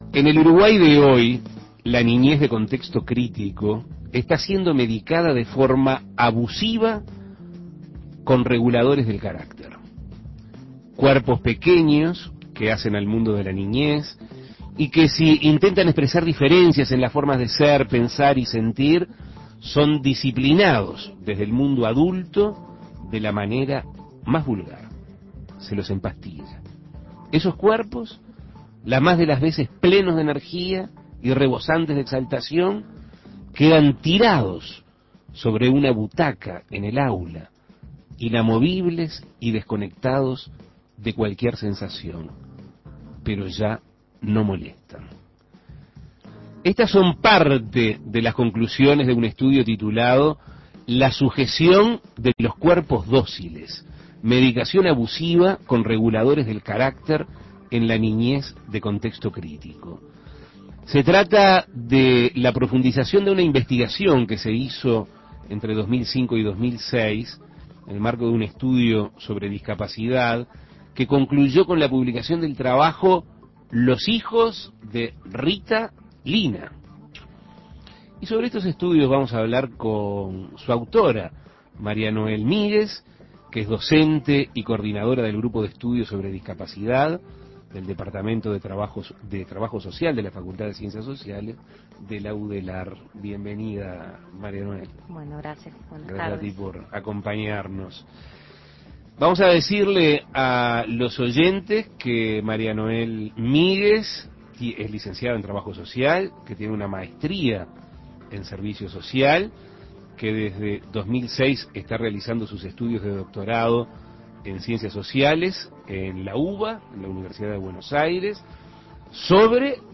Entrevistas Abusivo suministro de medicación a niños Imprimir A- A A+ En el Uruguay de hoy se está medicando de forma abusiva a los niños de contexto crítico con reguladores del carácter.